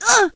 shelly_hurt_03.ogg